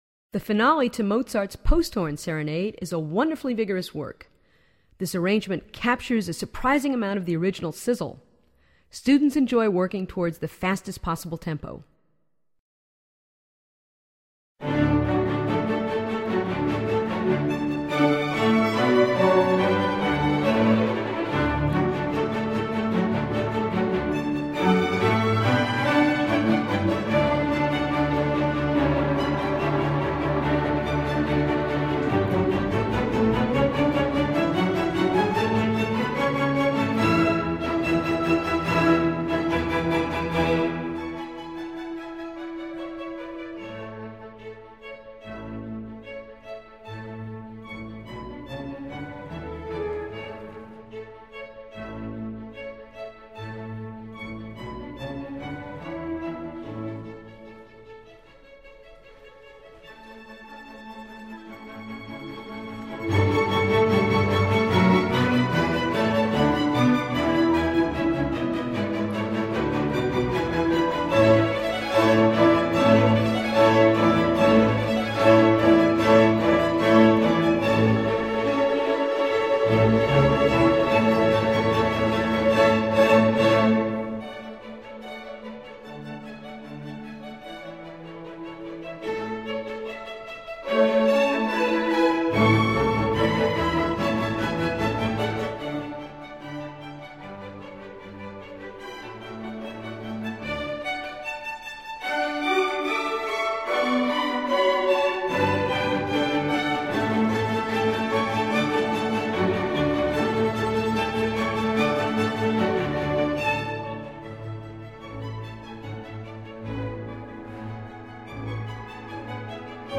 Voicing: SO Set C